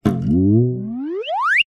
Звуки батута
На этой странице собраны звуки батута: прыжки, отскоки, скрип пружин и другие эффекты.
Звук прыжков человека на батуте